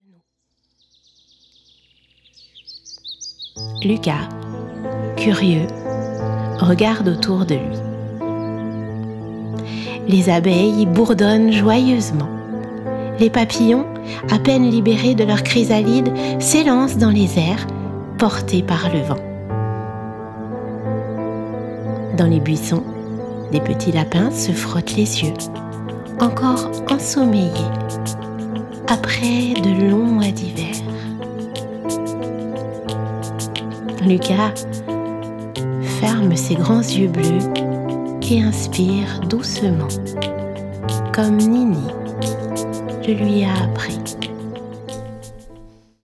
Inclus : un livre audio enchanteur et immersif !
Sa voix douce et expressive plonge les jeunes lecteurs dans un univers enchanteur, portée par une musique immersive qui accompagne chaque émotion du récit. Les mélodies délicates et les sons de la nature renforcent la poésie de l’histoire, rendant l’écoute aussi apaisante que captivante.